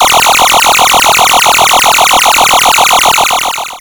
NFF-laser-storm.wav